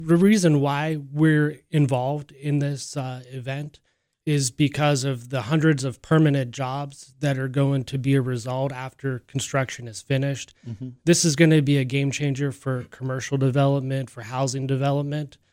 In an interview on Indiana in the Morning on Wednesday